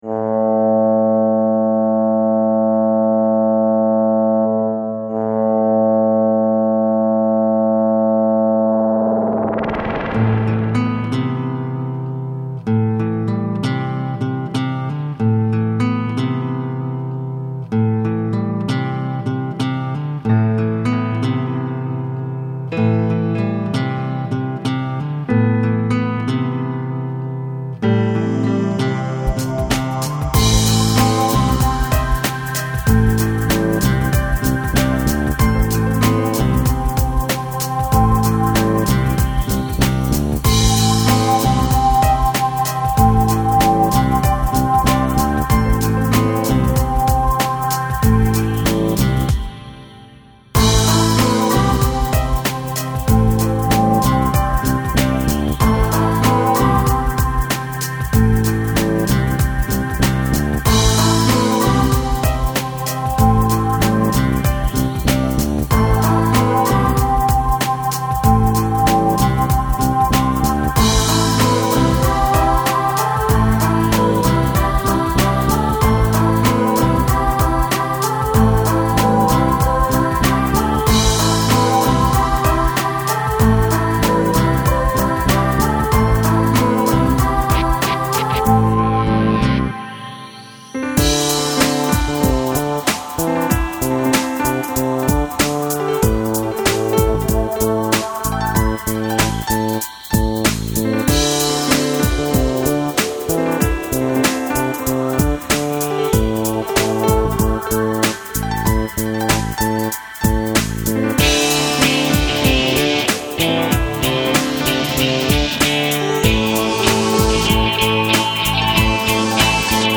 some sample instrumental tracks: